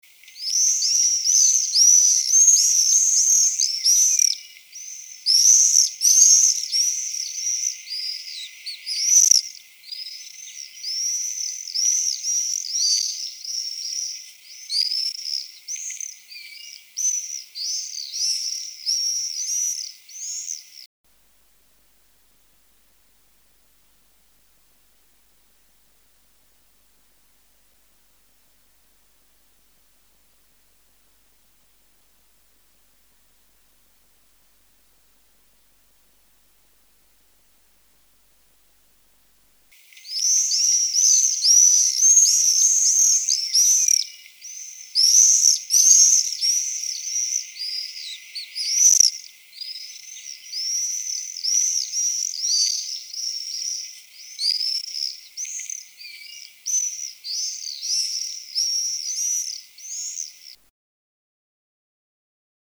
Gierzwaluw-geluiden
Van de geluiden die ze heeft opgenomen heeft ze een selectie gemaakt om af te spelen in kolonies, om te zien hoe gierzwaluwen op deze geluiden reageren.
Gierzwaluwen
Geluid 1 (kolonie)